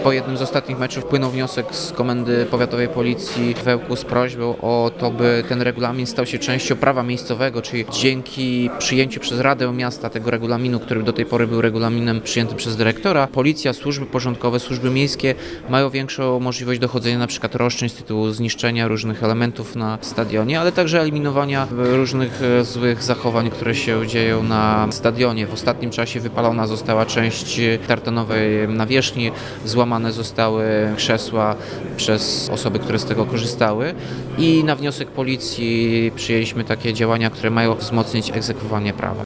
– Regulamin przyjęty przez Radę staje się częścią prawa miejskiego, co ułatwia egzekwowanie przepisów – tłumaczy Tomasz Andrukiewicz, prezydent Ełku.